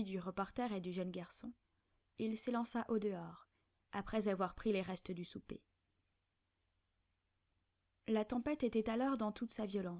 voice.wav